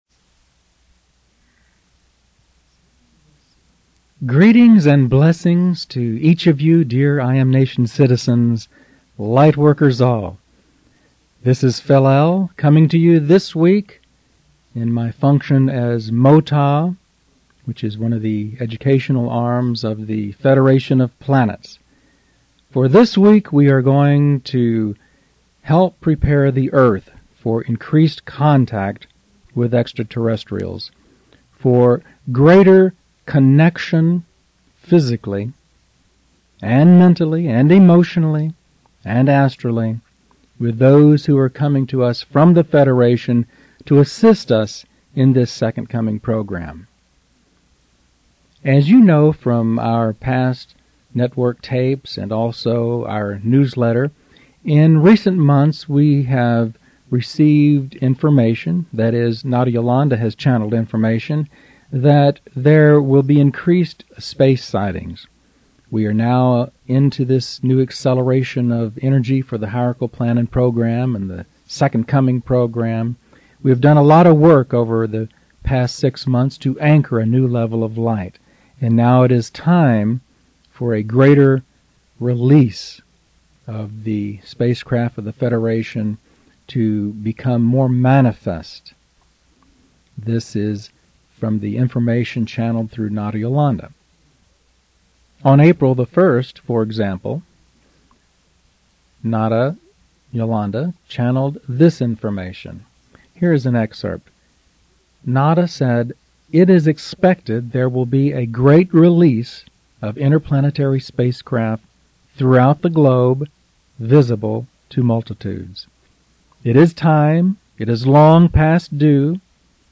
Because I recorded it extemporaneously, there is no written script.